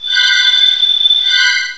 cry_not_gothitelle.aif